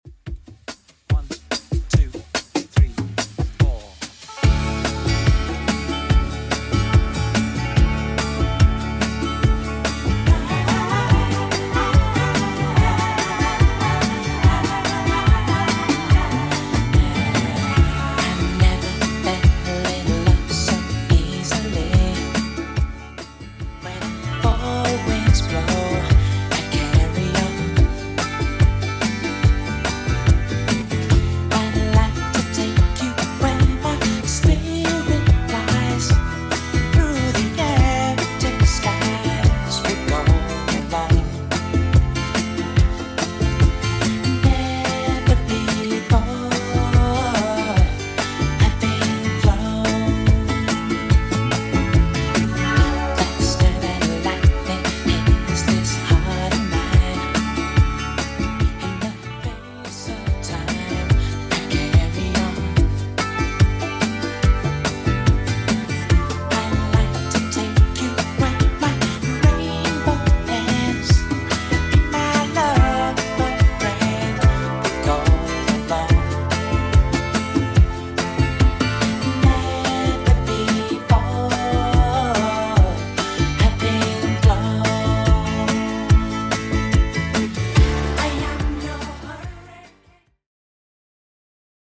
BPM: 144 Time